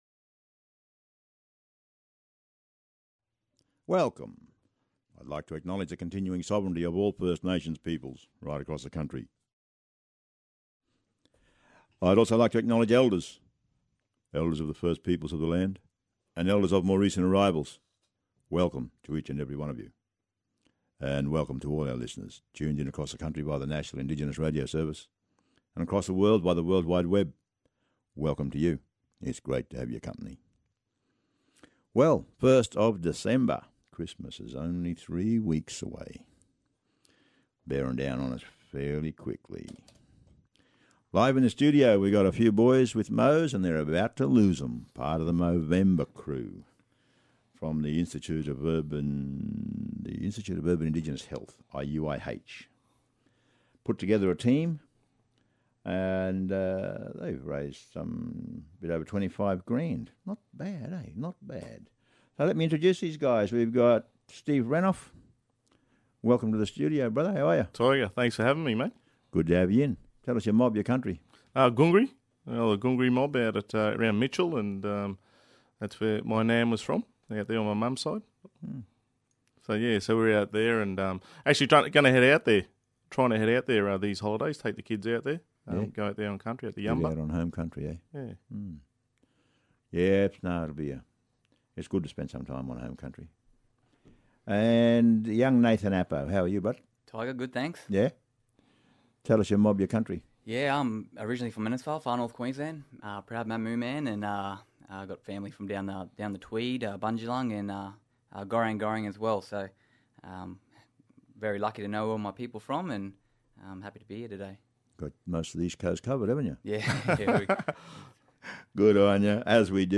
The Institute For Urban Indigenous Health, Deadly Choices ambassadors in the studio talking about Men’s Health an Movember.